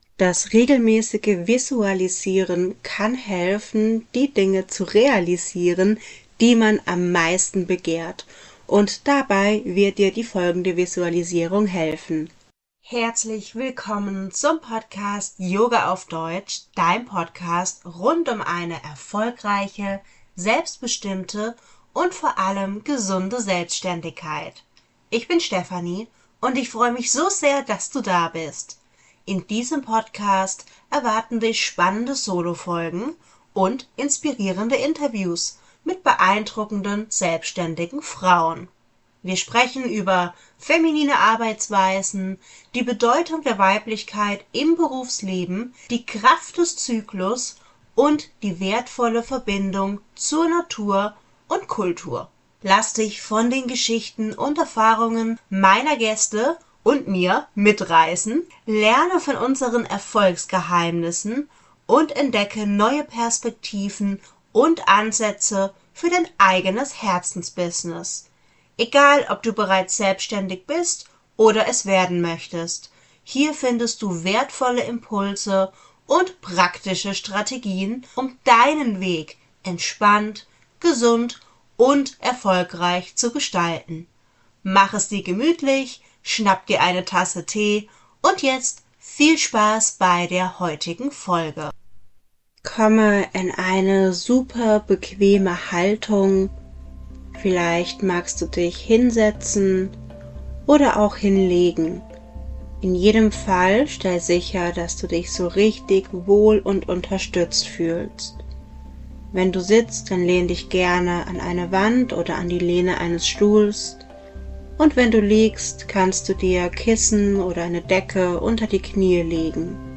Quantum Jumping Morgenmeditation